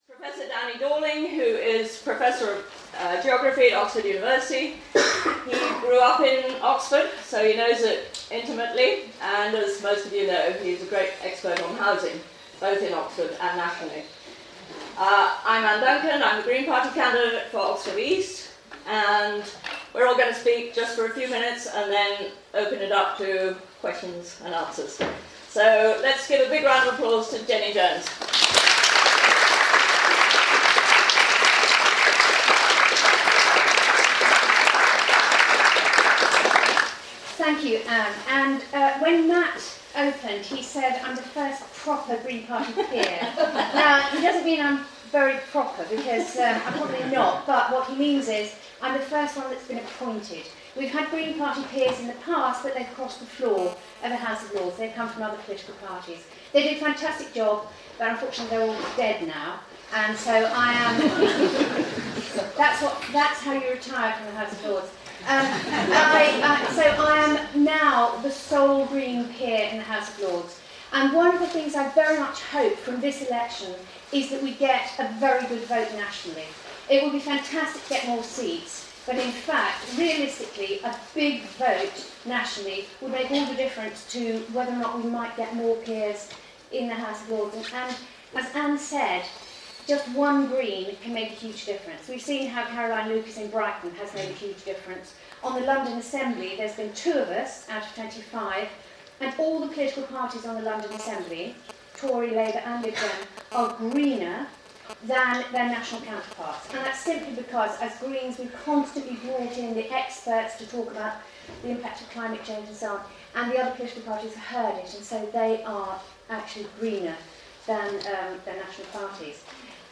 Friend’s Meeting House, Oxford, April 29th 2015